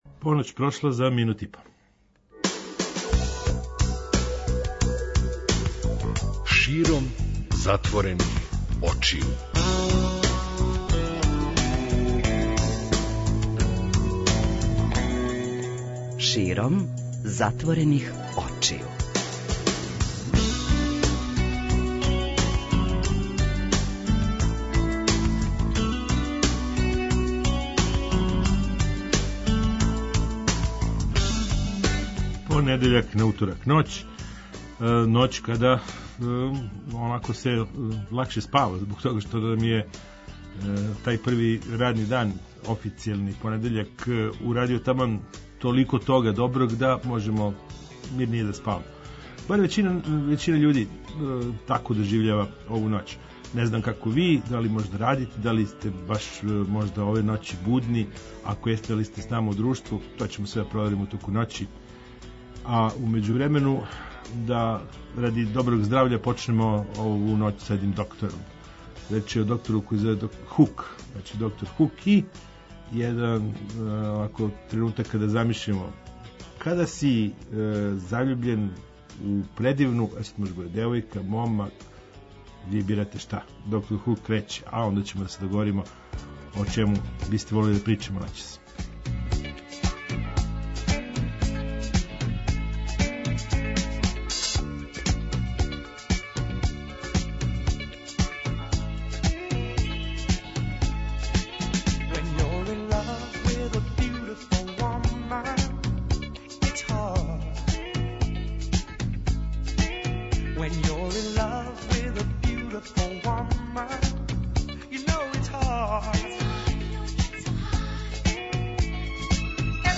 преузми : 53.90 MB Широм затворених очију Autor: Београд 202 Ноћни програм Београда 202 [ детаљније ] Све епизоде серијала Београд 202 Тешке боје Пролеће, КОИКОИ и Хангар Устанак Устанак Устанак